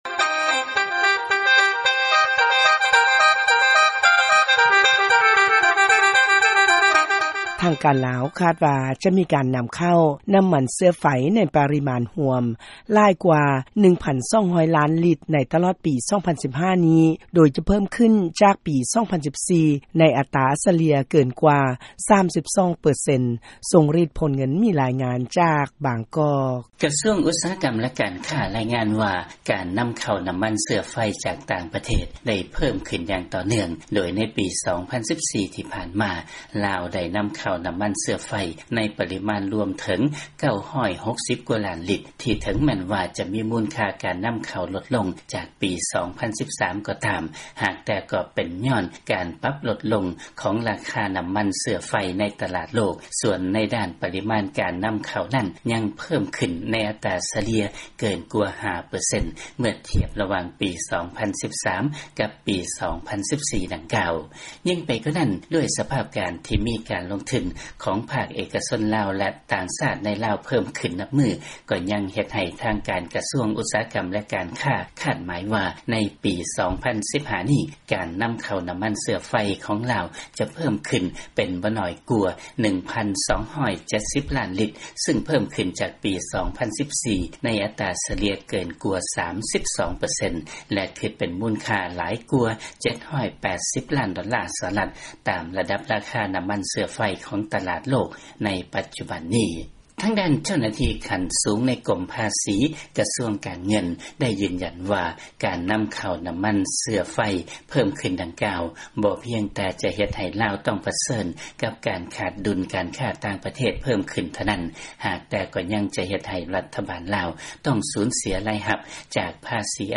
ຟັງລາຍງານ ລາວຈະນຳເຂົ້ານ້ຳມັນເຊື້ອໄຟ ຫຼາຍກວ່າ 1,200 ລ້ານລິດ ໃນປີ 2015.